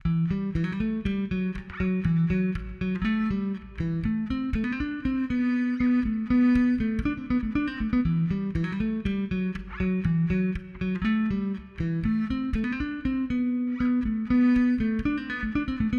Вот пример семплированного баса, к сожалению не добиться такой яркости от моего Sr1205, а если добиться то вместе с этой яркостью столько всего повылазит.